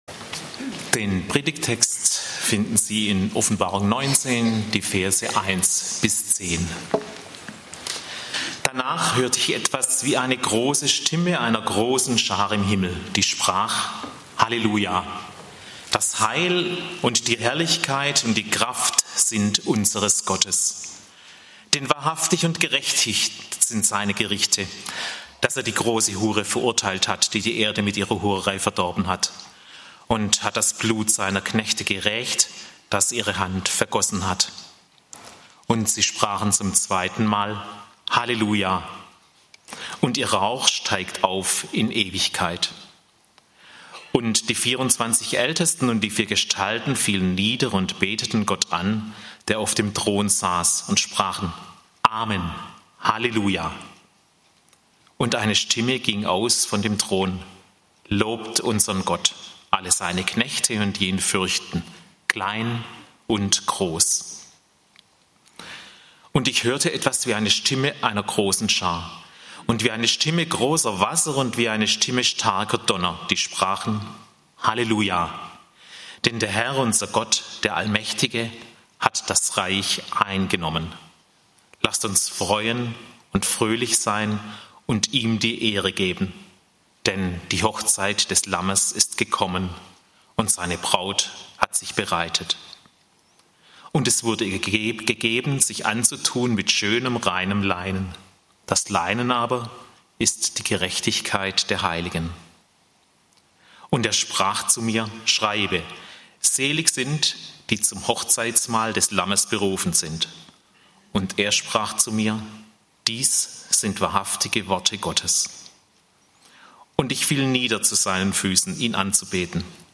Das Fest beginnt (Offb. 19, 1-10) - Gottesdienst